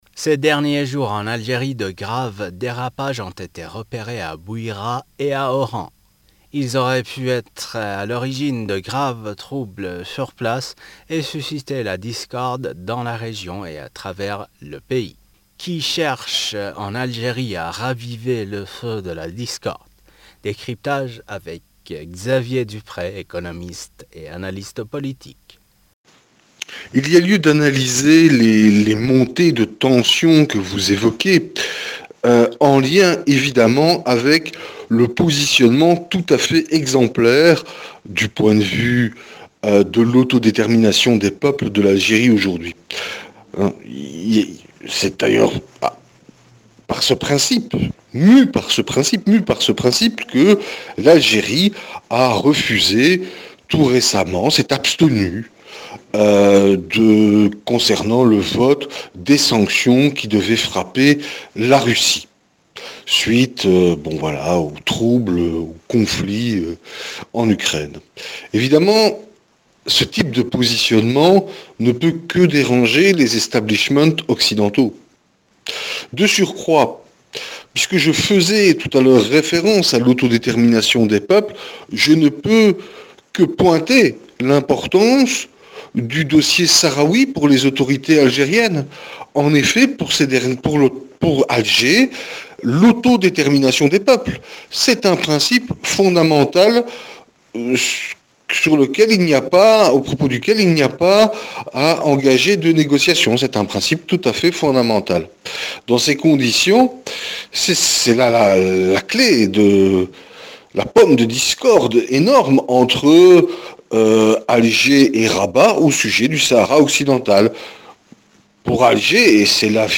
économiste et analyste politique nous répond.